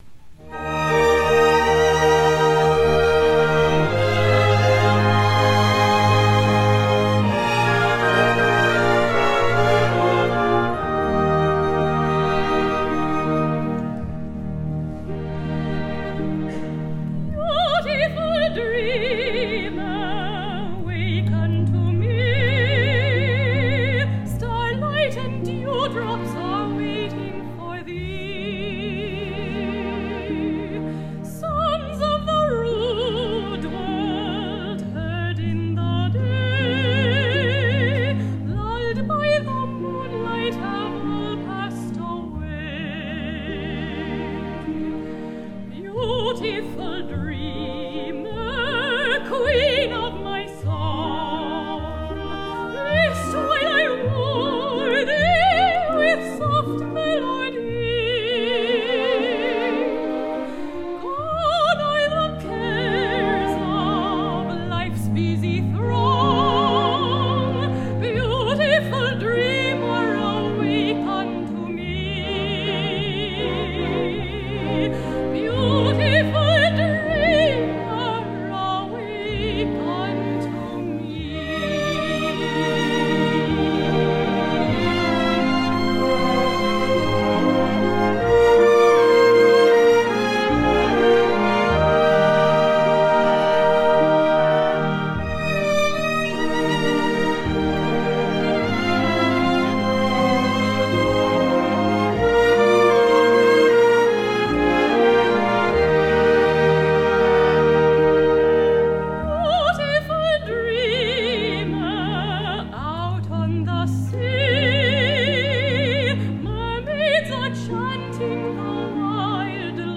Theme: Americana Category: Voices with Orchestra